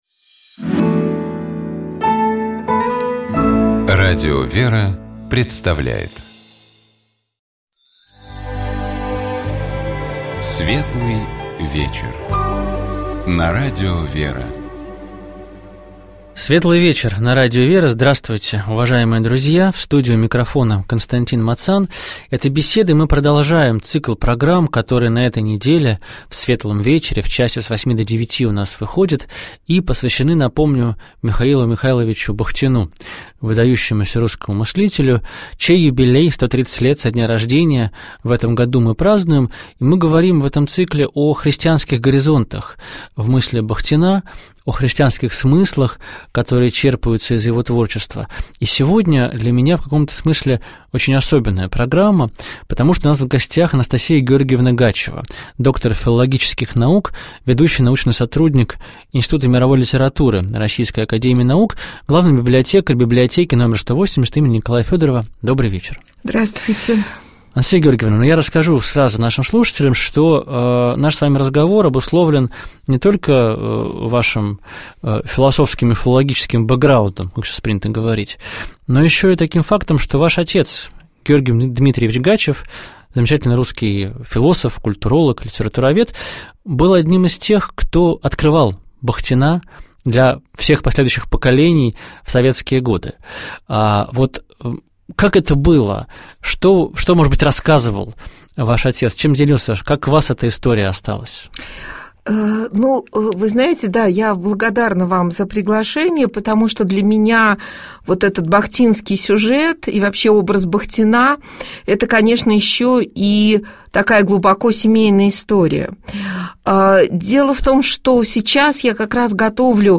Ведущая программы